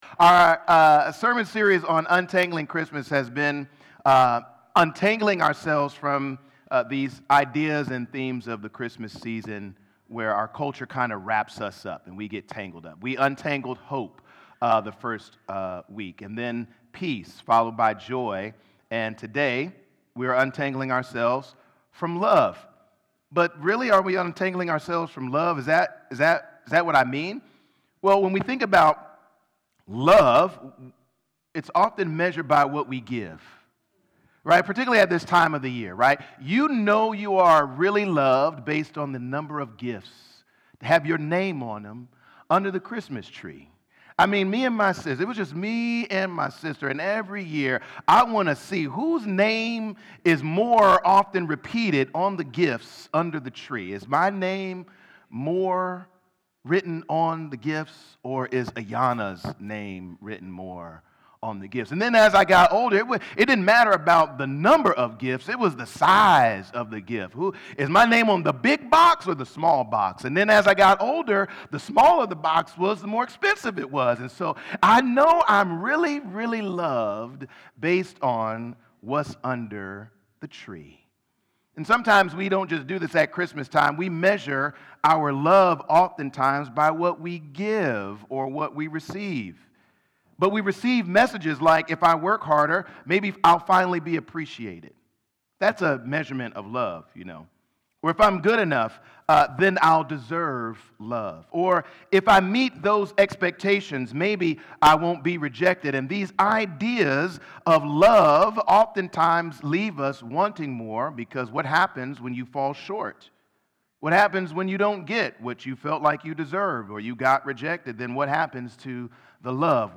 Sermons | Sanctuary Columbus Church